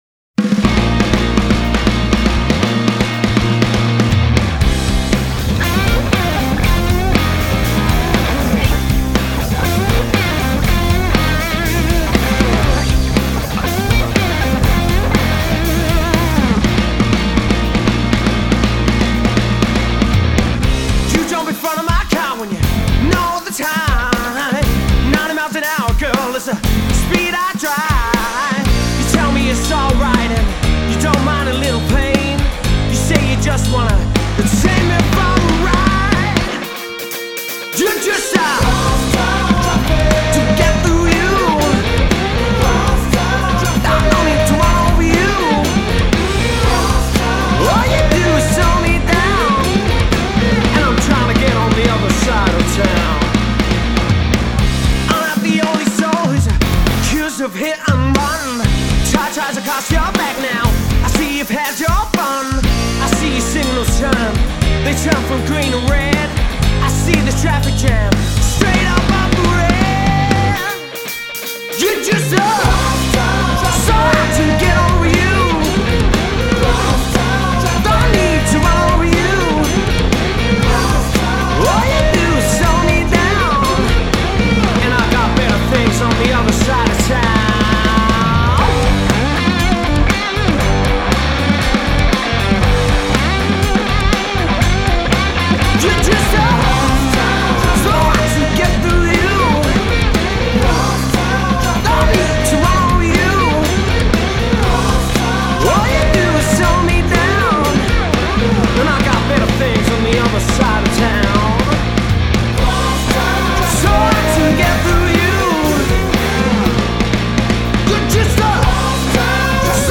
energetic
From £1,372 + travel | Wedding / Party Band